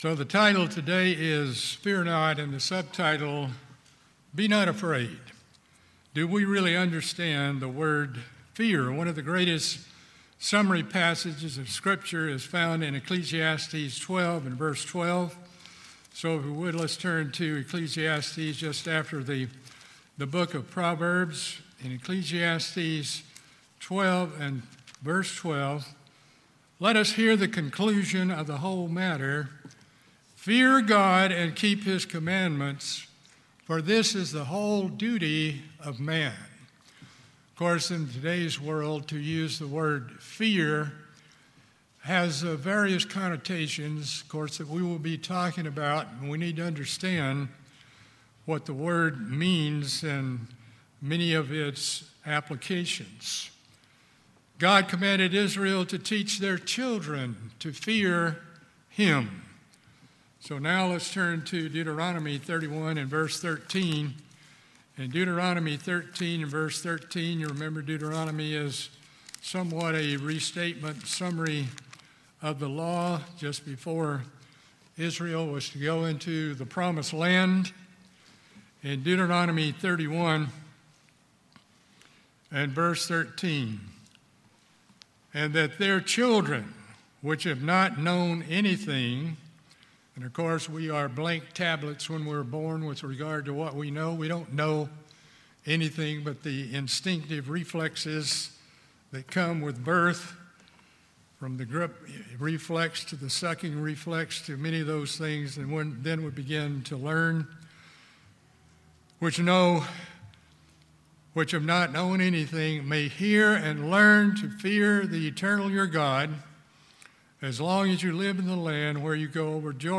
In this sermon we show that the Hebrew and Greek words translated as fear in scripture have a wide range of meanings. We are to fear God in the right way and hold Him in reverence, awe, respect and thanksgiving.